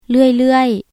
ເລື່ອຍໆ    → ເລື້ອຍໆ 　/lɯ̂ay lɯ̂ay/　「ずっと」